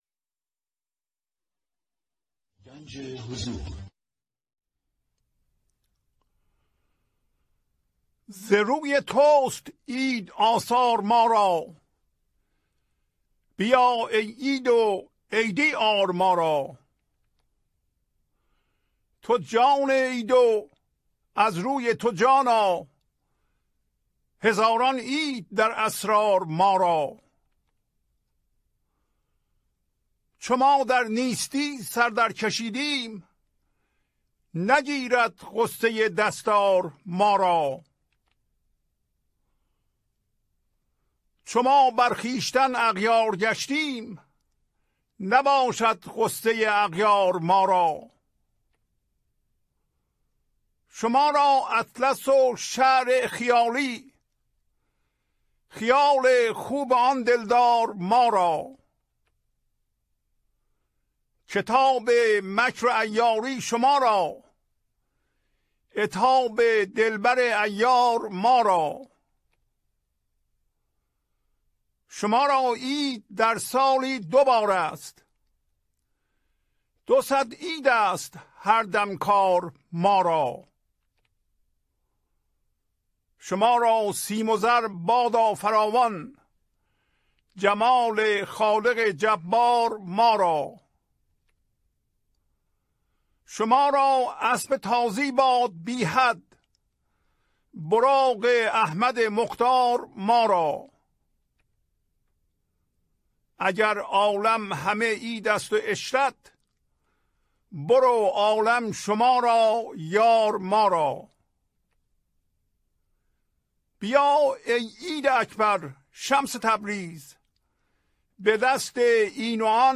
خوانش تمام ابیات این برنامه - فایل صوتی
1026-Poems-Voice.mp3